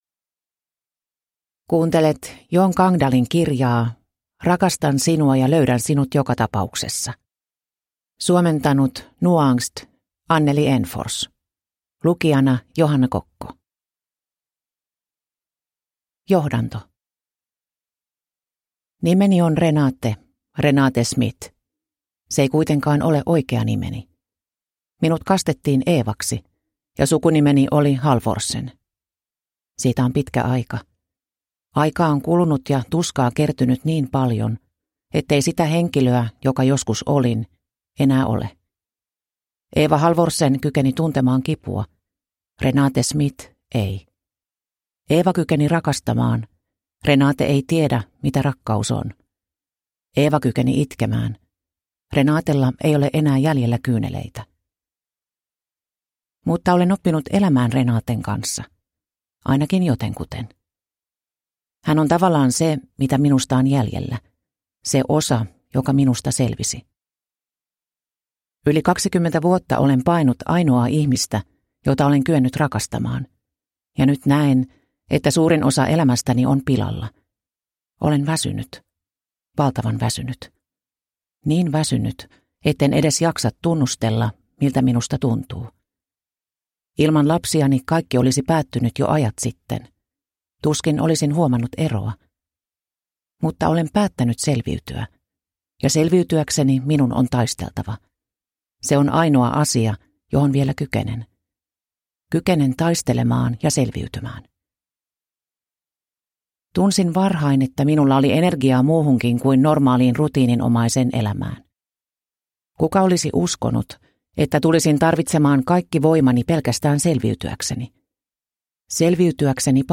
Rakastan sinua ja löydän sinut joka tapauksessa (ljudbok) av Jon Gangdal